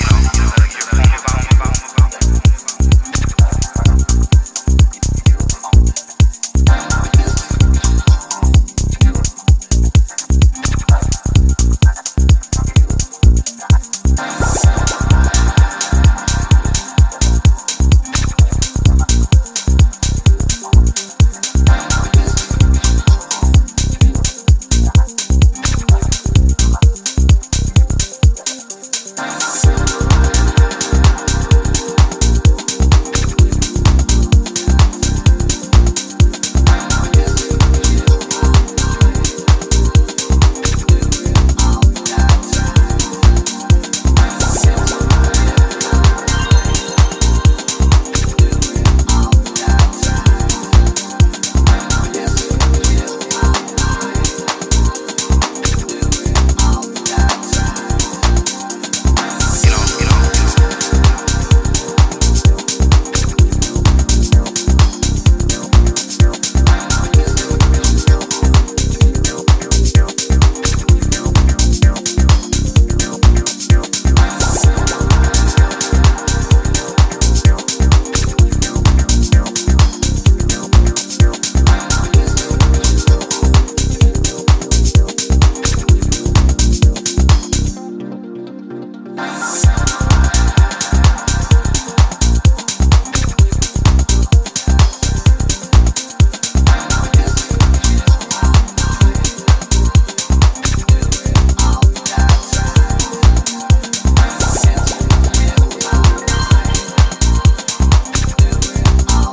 this record still bangs.
perfect mix of squelchy tech and vocal loveliness